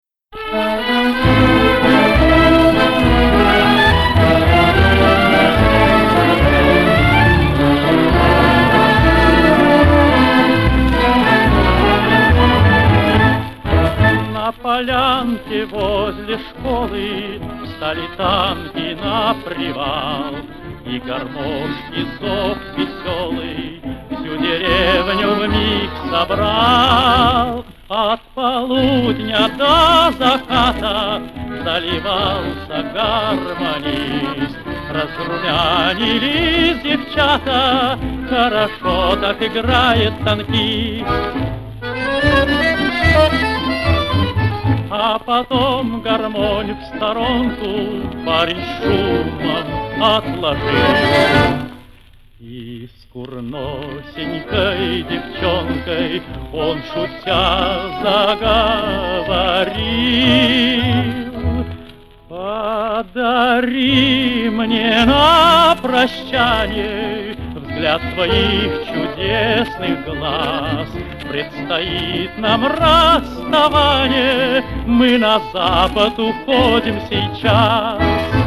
Фрагмент песни